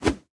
swing_clothshort_c.ogg